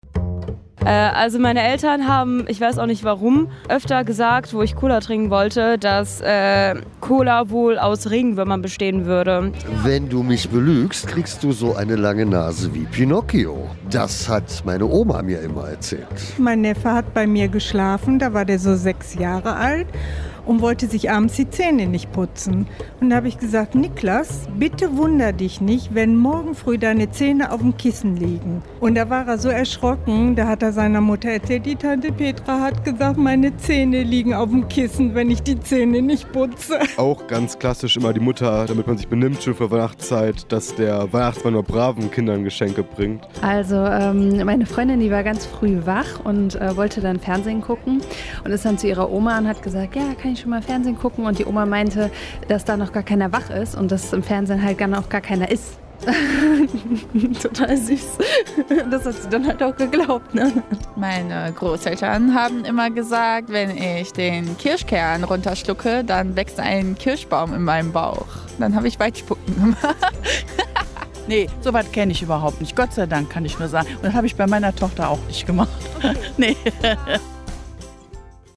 collage_kinderluegen_1.mp3